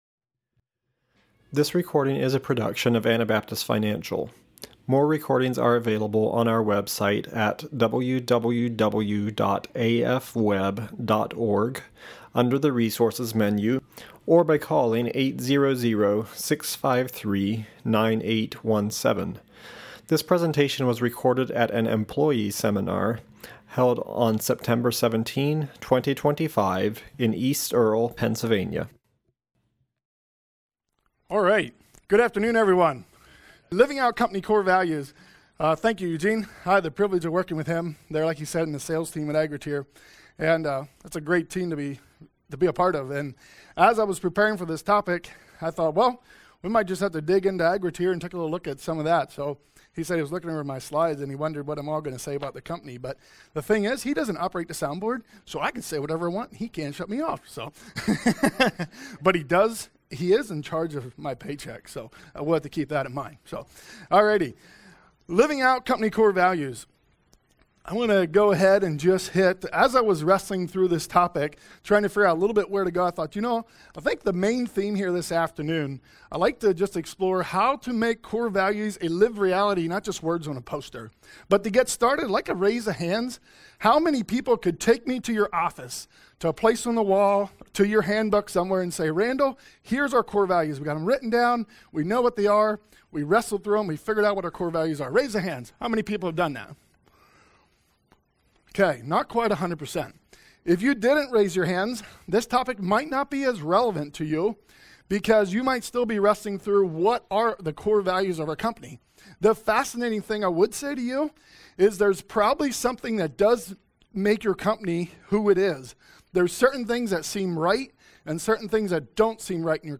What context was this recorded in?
Pennsylvania Employee Seminar 2025